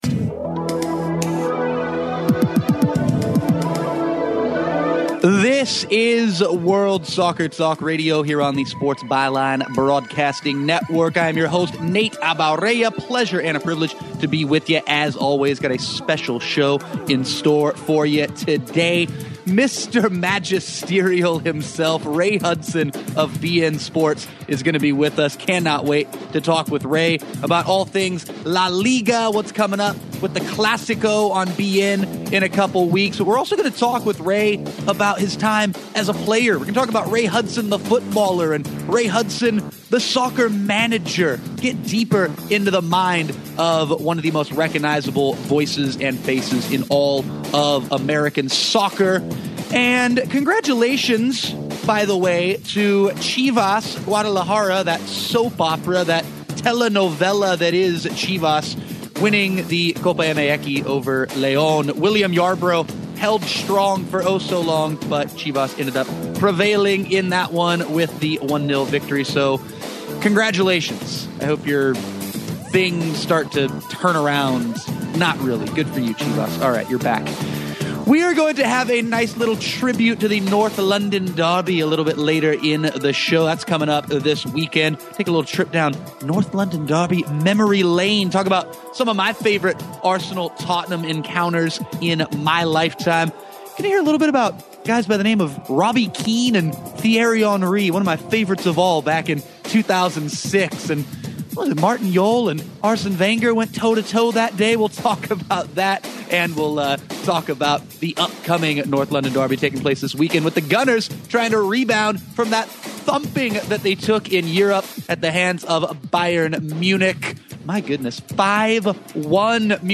Ray Hudson interview; World Soccer Talk Radio (11.5.15)
Listen to the latest episode of World Soccer Talk Radio featuring an interview with beIN SPORTS co-commentator Ray Hudson. During the interview, Ray discusses a range of topics including exciting news about beIN SPORTS’ coverage of el Clasico, his opinion on whether he’d like to be featured as an announcer […]